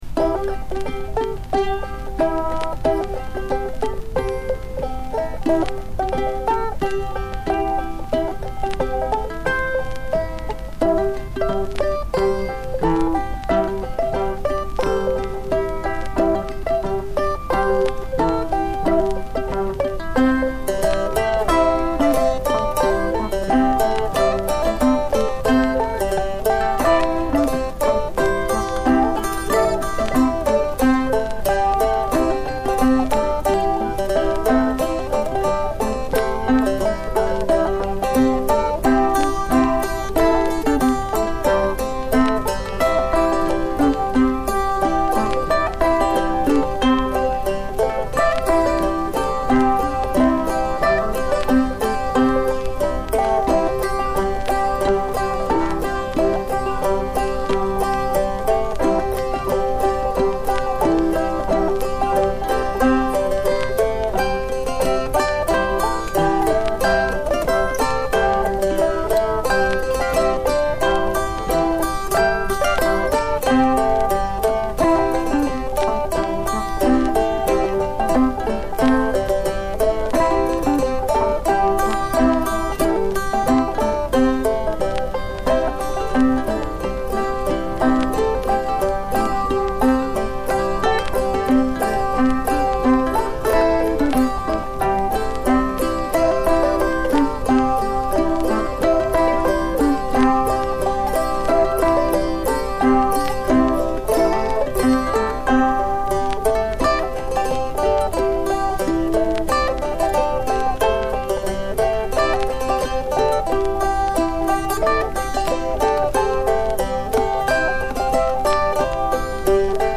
Accordion, Mandola, Mandolin